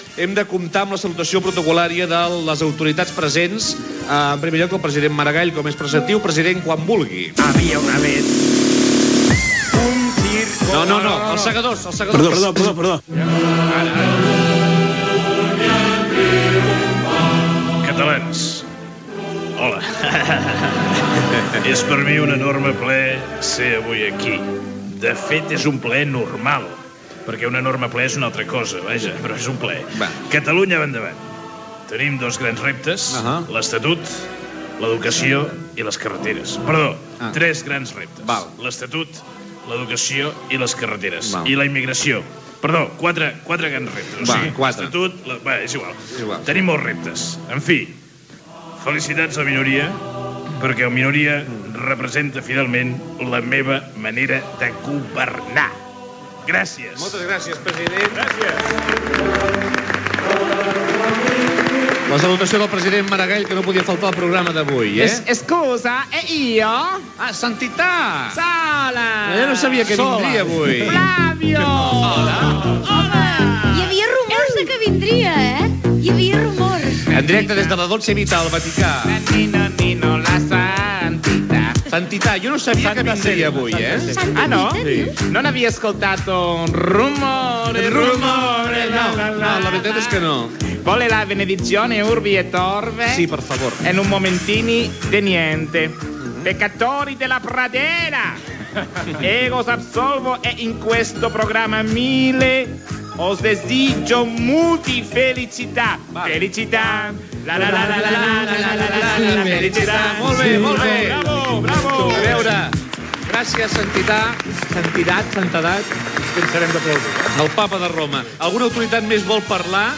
Fragment de l'emissió del programa número 1000 fet des del Teatre Villarroel de Barcelona.
Salutació de les diverses personalitats (imitacions del president de la Generalitat Paqual Maragall, el sant Pare, l'alcalde Clos, el president del Govern espanyol Rodríguez Zapatero, Francesc Macià i Francisco Franco)
Entreteniment